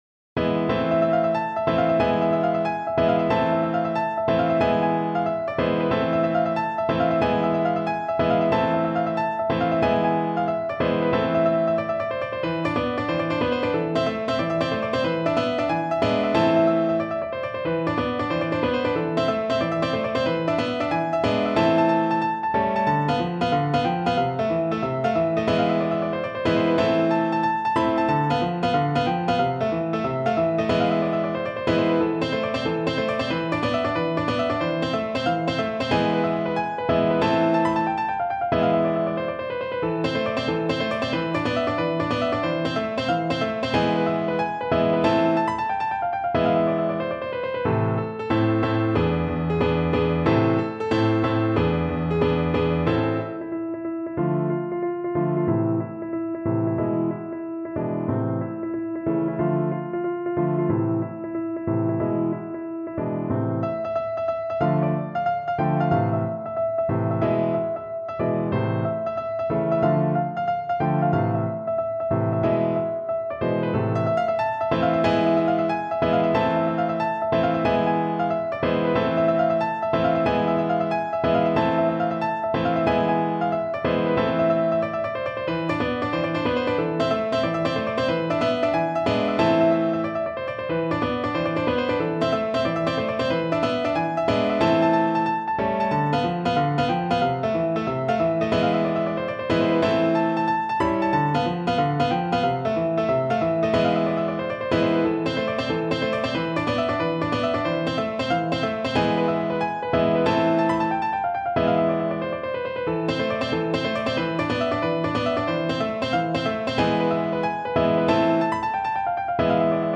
Free Sheet music for Piano
No parts available for this pieces as it is for solo piano.
Pizzica music is known for its fast-paced rhythms, intricate melodies, and infectious energy.
A minor (Sounding Pitch) (View more A minor Music for Piano )
6/8 (View more 6/8 Music)
Molto allegro .=c.184
Piano  (View more Intermediate Piano Music)
Classical (View more Classical Piano Music)